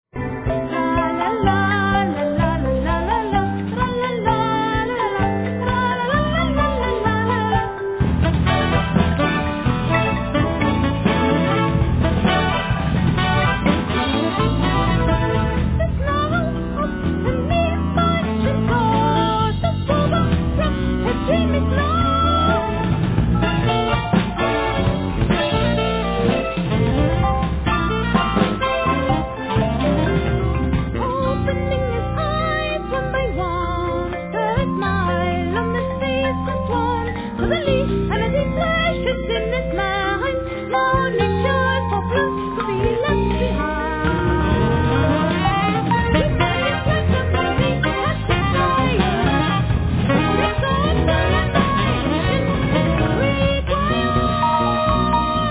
Vocals
Soprano+Tenor Sax
Yamaha Grantouch(Piano)
Marimba,Korg 01W,Programming & conducting
5-string e.bass
Drums